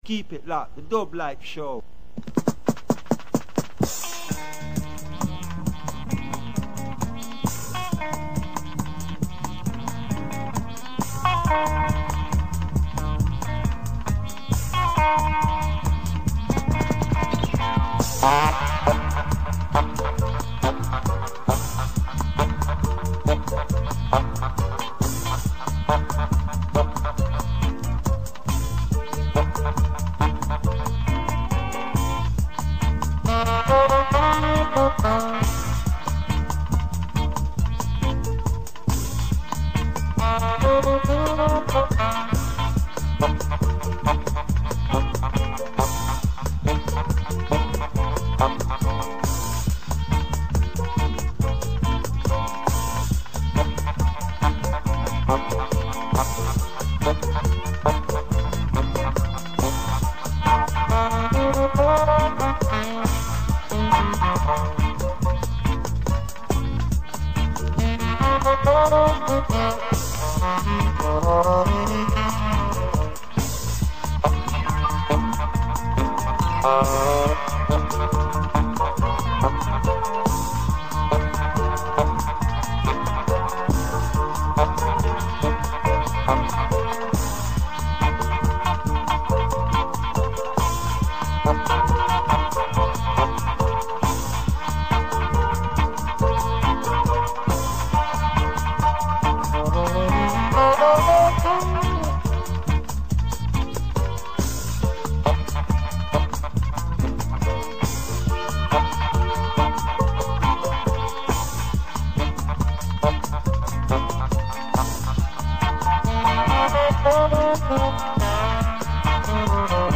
Dublife Radio Show every Tuesday (6 pm to 8pm GMT) missing the 1st 20-30 mins , streaming kept breaking down!